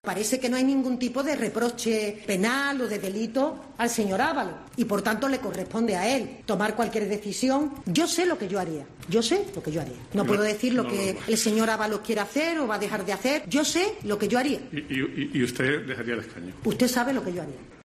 "Yo sé lo que yo haría. No puedo decir lo que el señor Ábalos quiere hacer o va a dejar de hacer, yo sé lo que yo haría", ha añadido María Jesús Montero, que al comentario del moderador del foro acerca de si ella "dejaría el escaño" ha respondido: "usted sabe lo que yo haría".